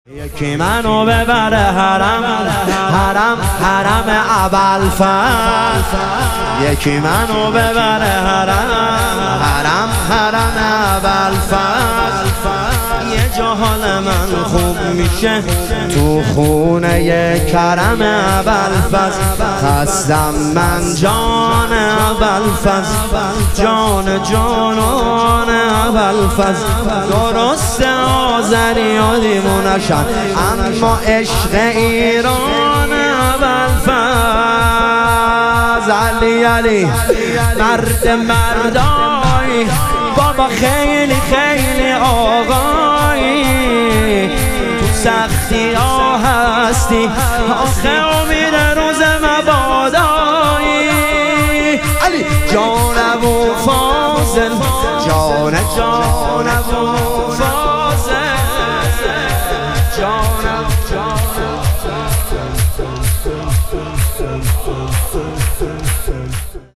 شور
شب شهادت امام هادی علیه السلام